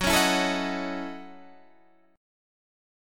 F#m13 chord